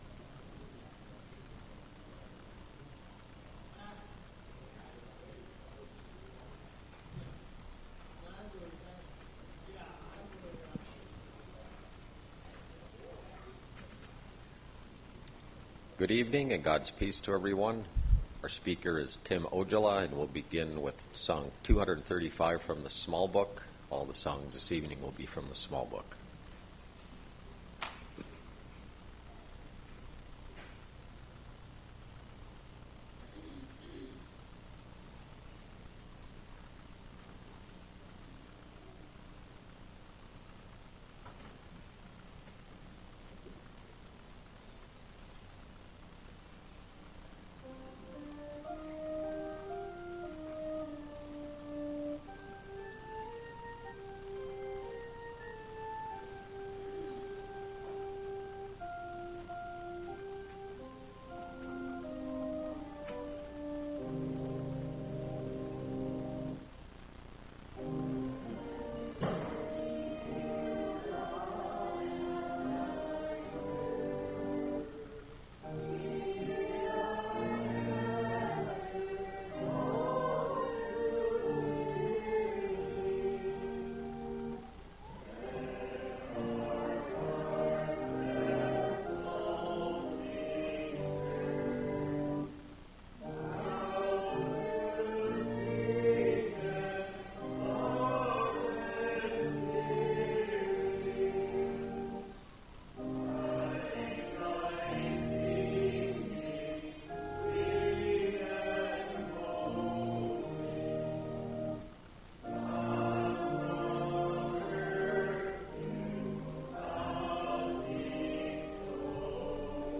Suomalaissyntyinen mies puhuu.
Tämä on luterilainen lestadiolainen kirkko USAssa.